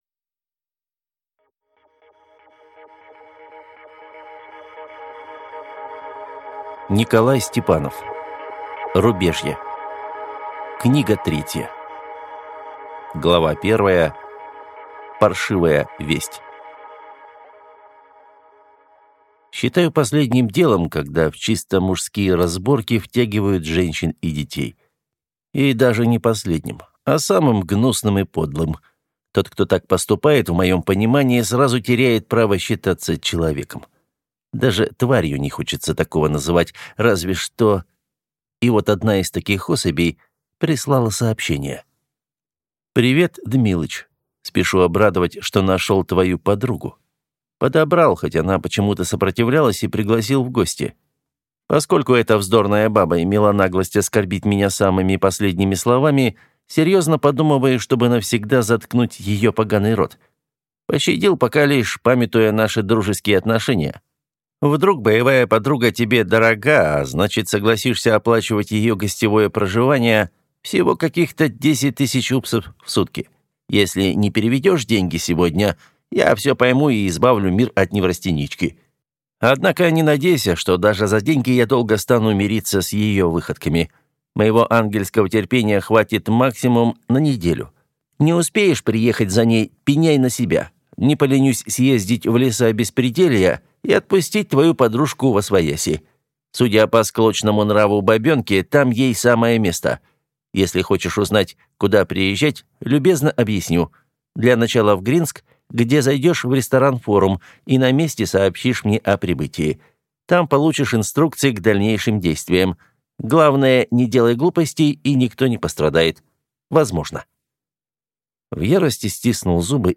Аудиокнига Рубежье 3 | Библиотека аудиокниг